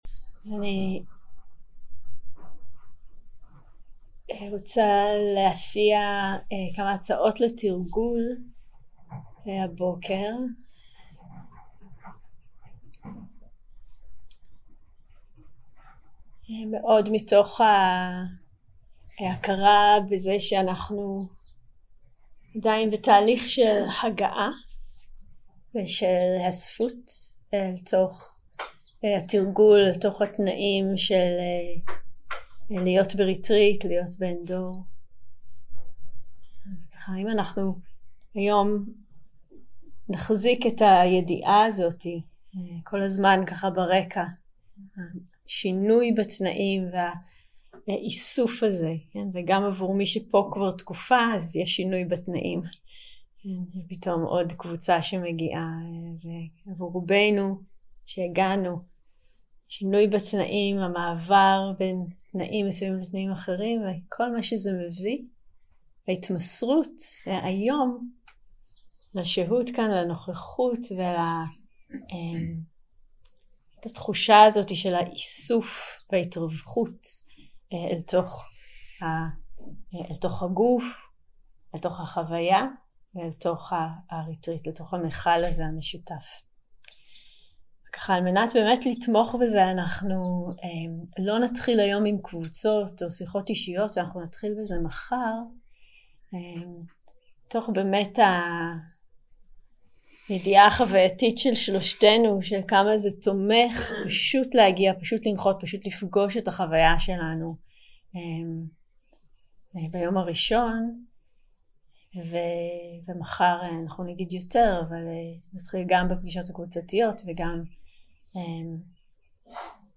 יום 2 – הקלטה 2 – בוקר – הנחיות למדיטציה – התרווחות והזנה 0:00 0:00 סוג ההקלטה: Dharma type: Guided meditation שפת ההקלטה: Dharma talk language: Hebrew